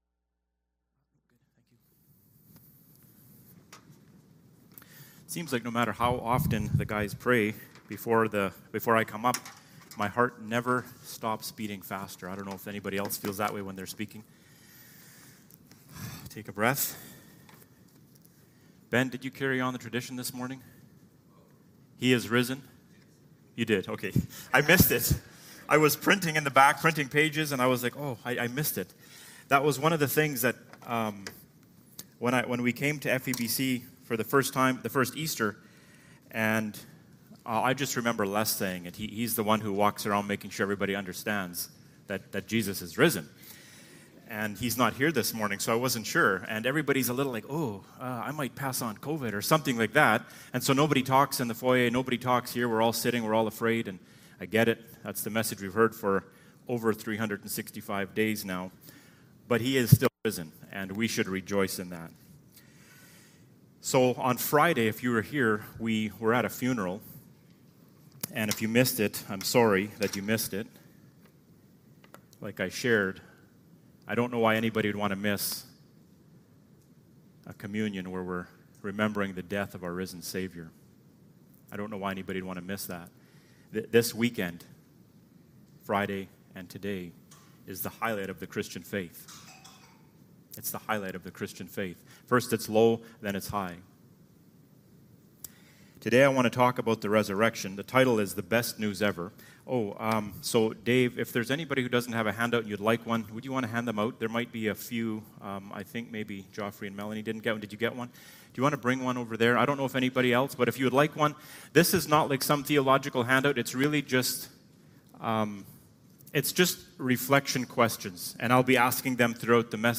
Passage: 1 Thessalonians 4:13-16 Service Type: Sunday Morning